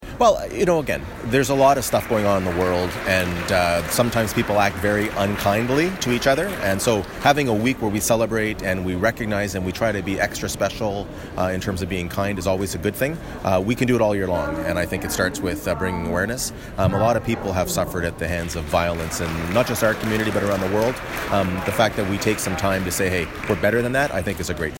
The 23rd annual Violence Awareness Random Acts of Kindness week began tonight at the Quinte Mall in Belleville.